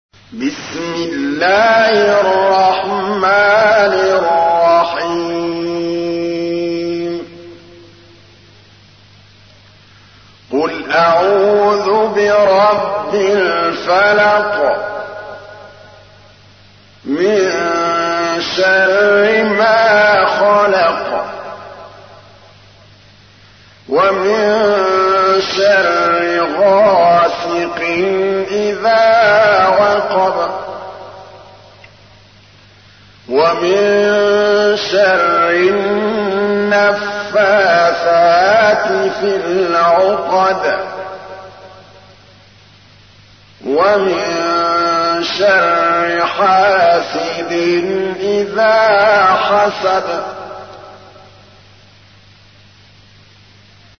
تحميل : 113. سورة الفلق / القارئ محمود الطبلاوي / القرآن الكريم / موقع يا حسين